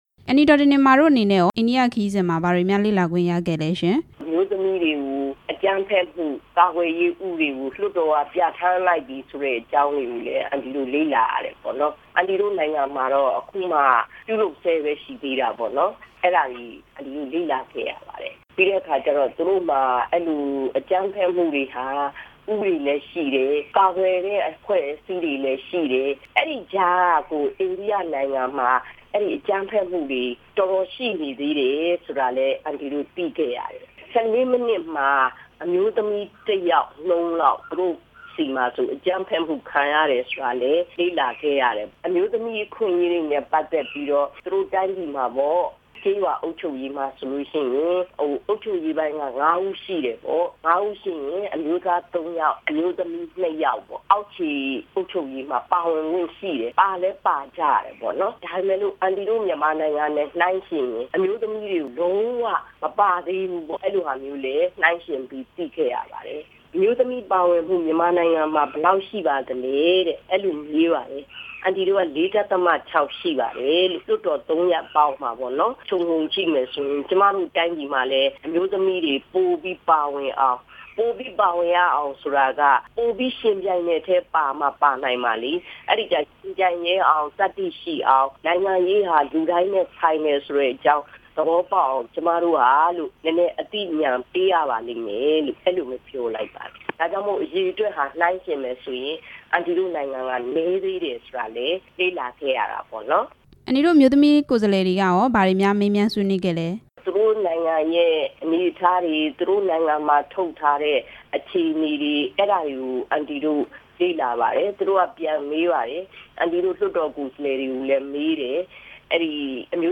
လွှတ်တော်ကိုယ်စားလှယ် ဒေါ်တင်တင်မာနဲ့ မေးမြန်းချက်